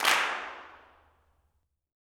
CLAPS 19.wav